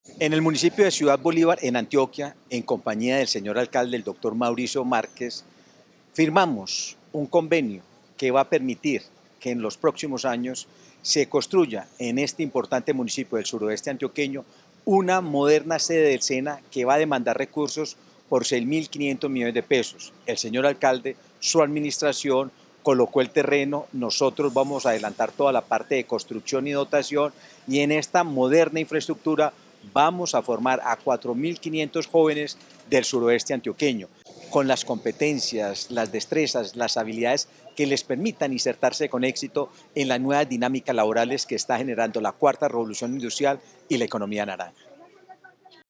AUDIO-DIRECTOR-GENERAL-SENA-CIUDAD-BOLIVAR-ENTREGA-LOTE-DG.mp3